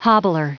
Prononciation du mot hobbler en anglais (fichier audio)
Prononciation du mot : hobbler